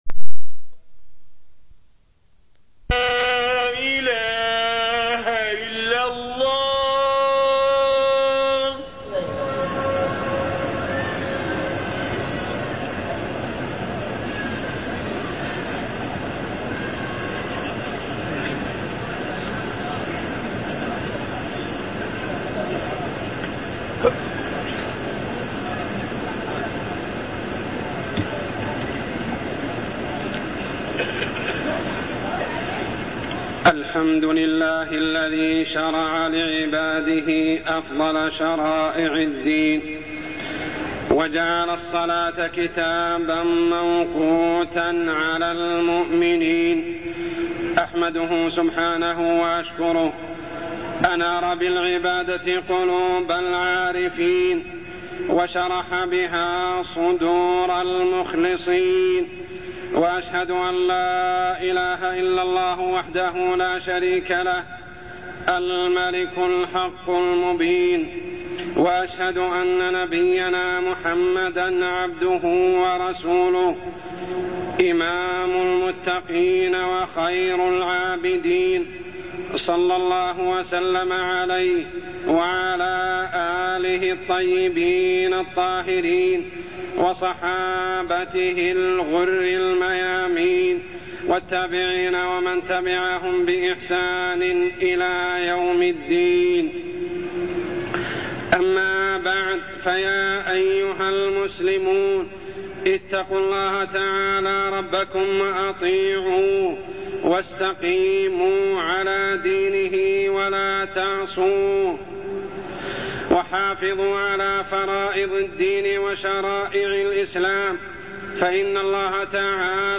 تاريخ النشر ١٨ شعبان ١٤٢٠ هـ المكان: المسجد الحرام الشيخ: عمر السبيل عمر السبيل فريضة الصلاة